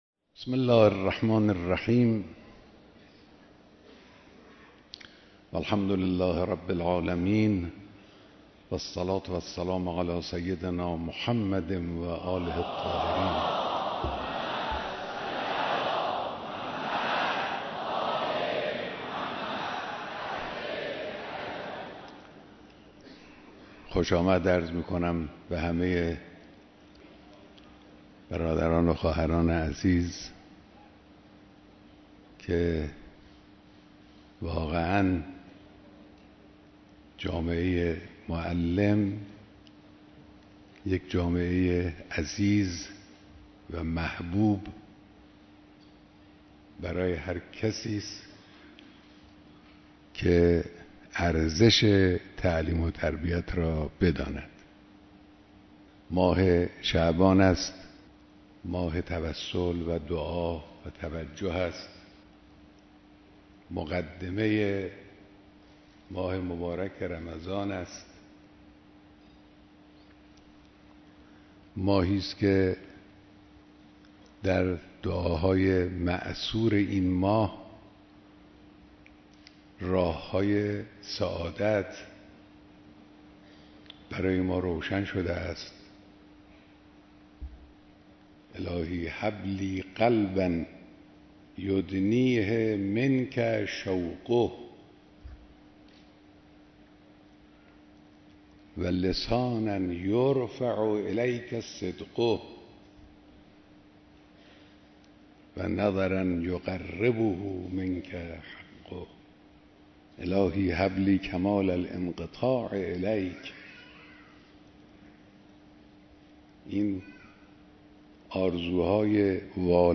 بیانات در دیدار هزاران نفر از فرهنگیان سراسر کشور